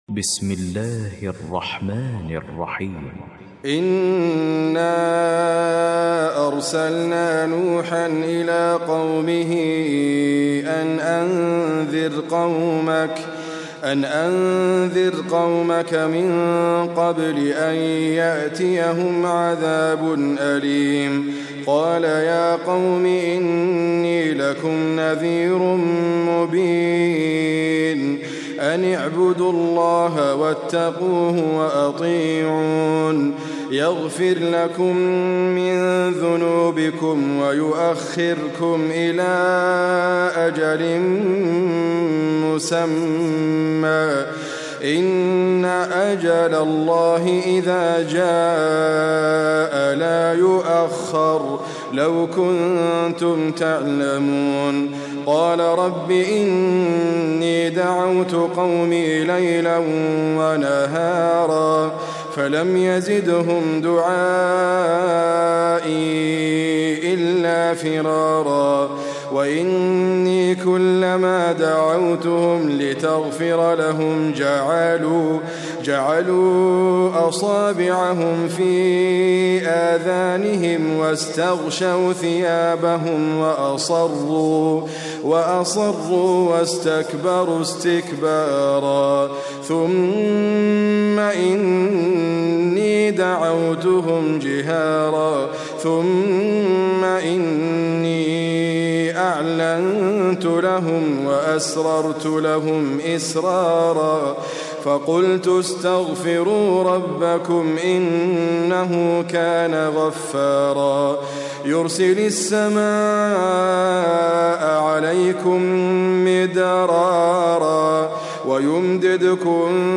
QuranicAudio is your source for high quality recitations of the Quran.